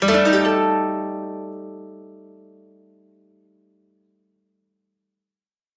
和楽器を鳴らした音、和風の情緒ある効果音がダウンロードできます。
箏（琴）のジャラランと上がっていく効果音